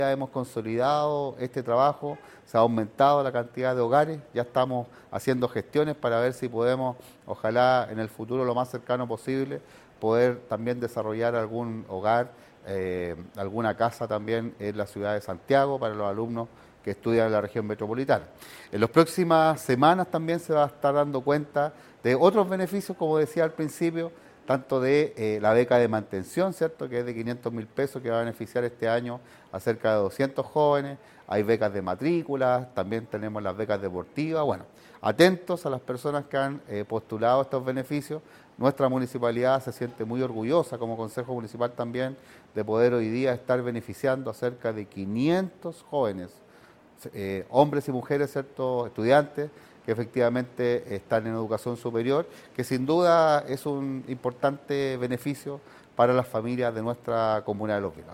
En este sentido, el edil también comentó acerca de los planes a desarrollar en un futuro, buscando ampliar el rango de ciudades con hogares universitarios, tal como se ha ido ejecutando año a año: